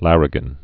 (lărĭ-gən)